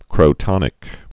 (krō-tŏnĭk)